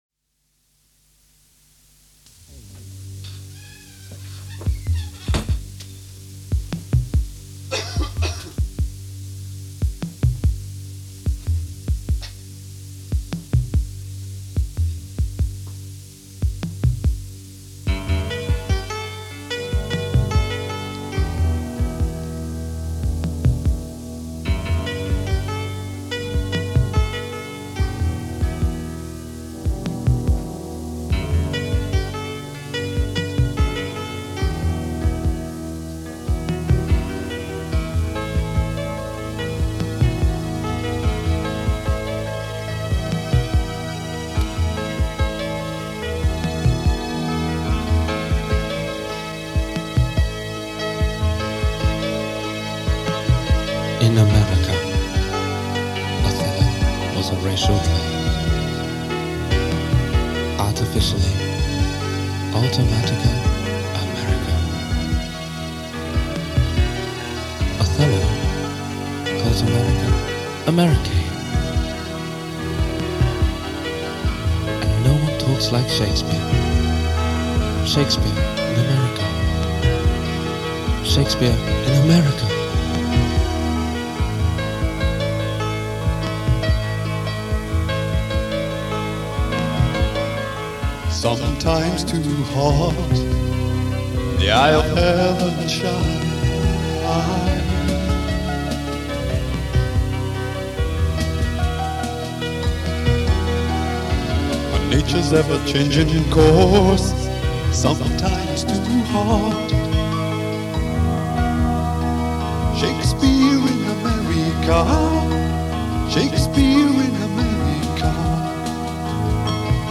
piano melody
Later a proper studio recording was made, but it lost some of the charm and atmosphere of the original. Nothing was released, but you can hear the demo here..